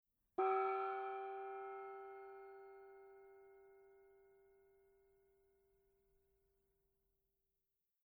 The Weiss Opera Gong delivers a classic tone with an upward glissando. These gongs are also called a Jing, bending gong or benders.
opera gong soft.mp3